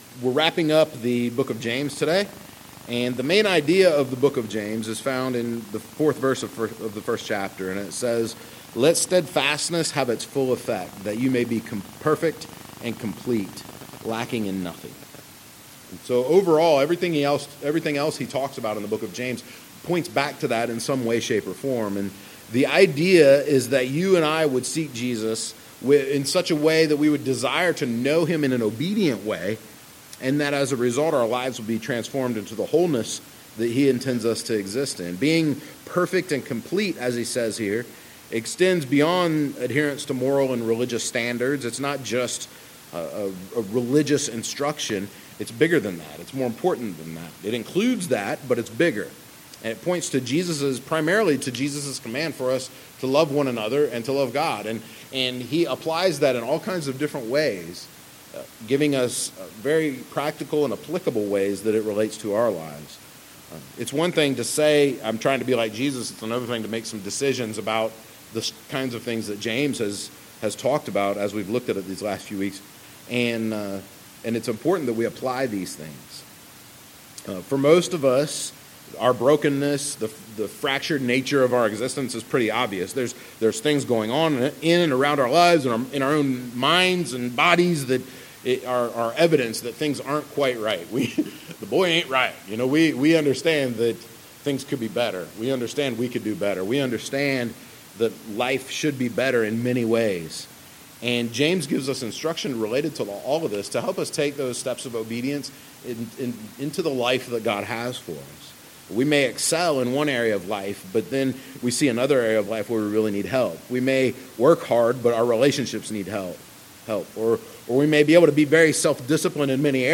**Audio recording has significant problems, but can still be used**